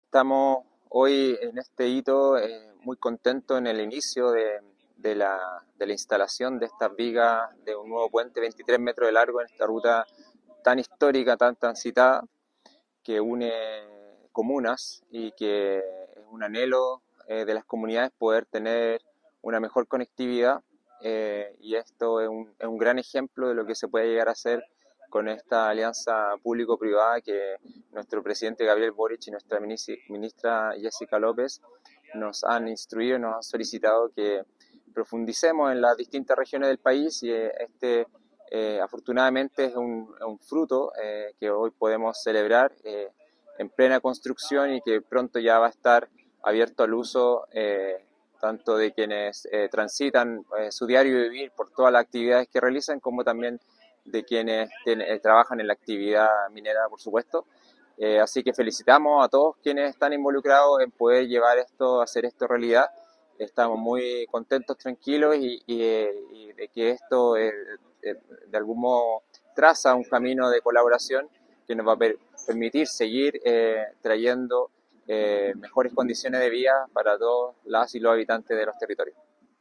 Respecto a este tipo de alianzas, el SEREMI de Obras Públicas, Javier Sandoval, indicó que